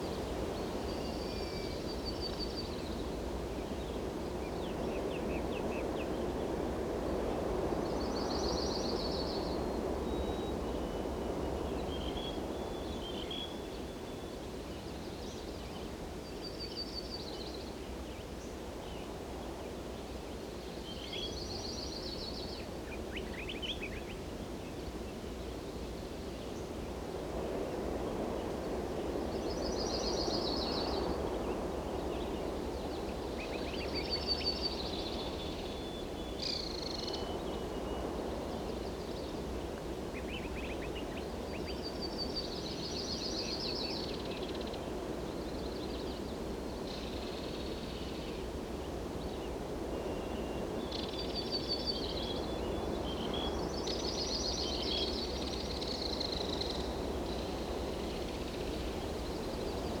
Forest Day.wav